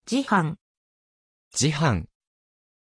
Prononciation de Jehan
pronunciation-jehan-ja.mp3